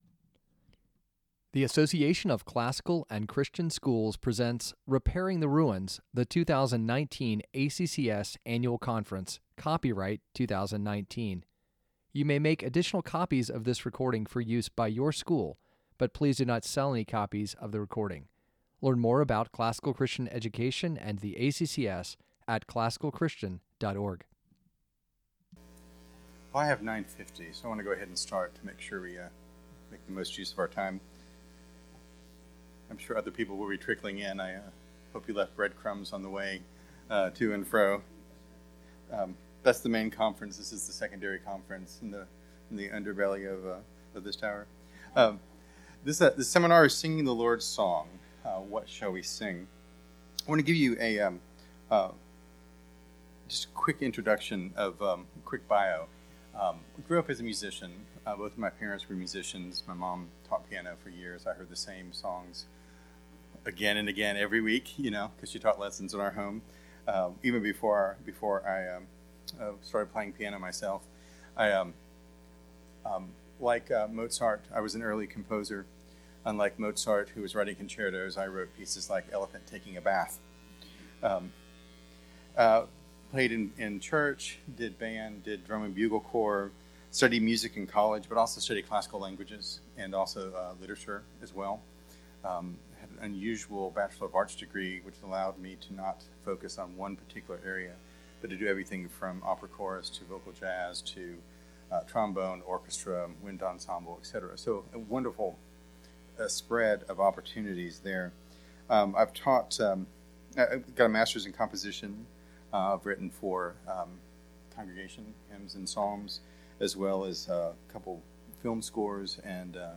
2019 Workshop Talk | 01:01:00 | All Grade Levels, Art & Music